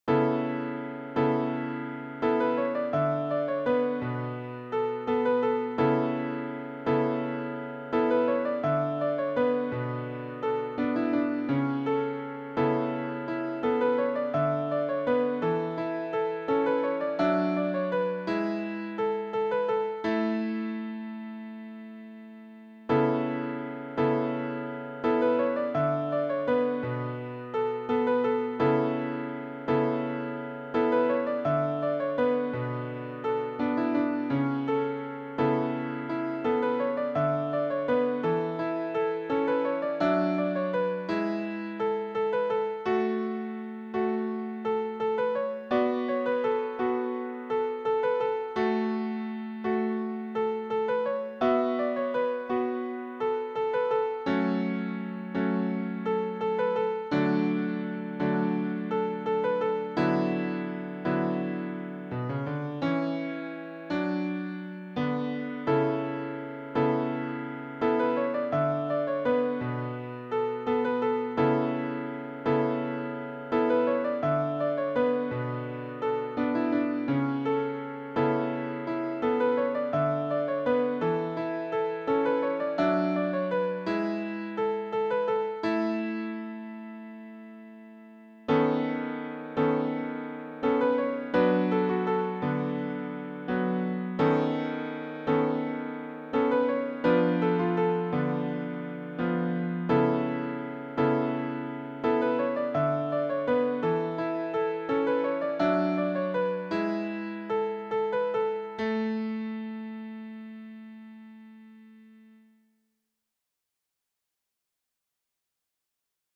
No. 30 "For My Reunited Parents" (Piano